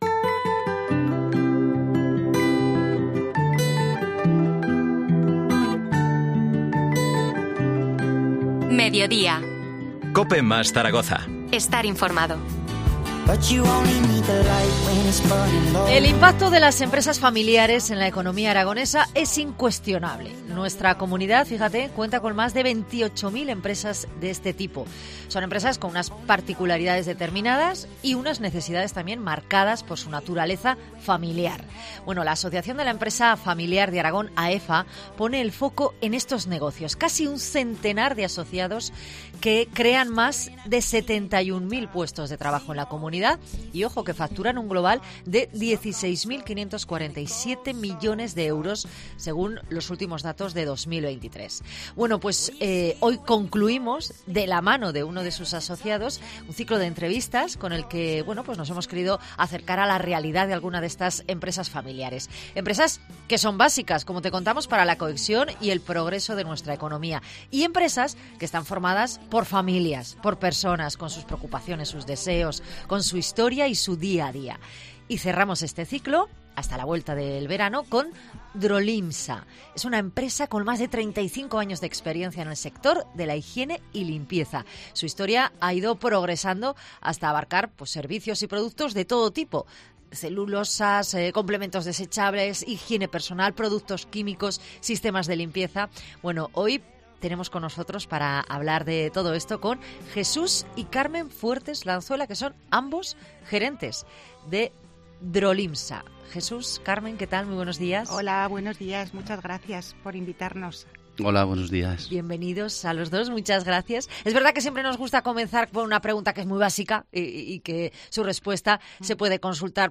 Entrevista AEFA